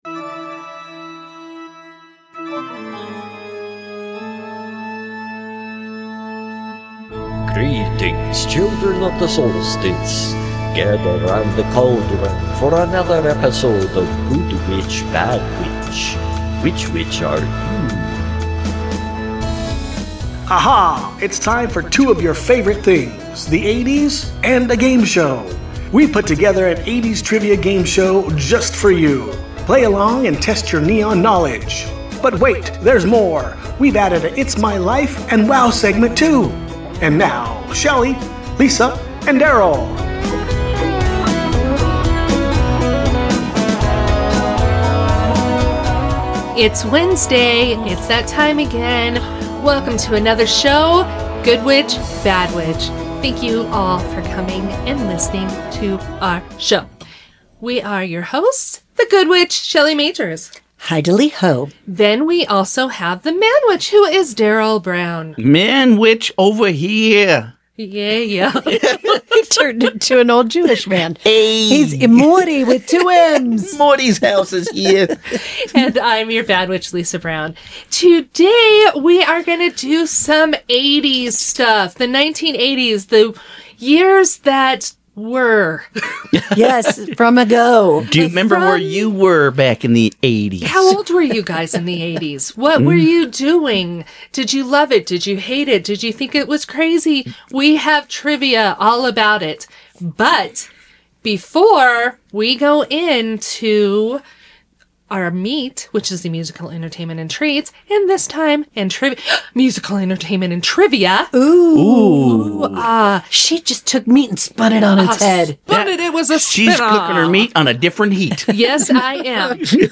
Two polar opposite best friends interview fascinating guests and dish the dirt. Topics range from urban legends and the metaphysical to true crime, music history and crazy animal facts.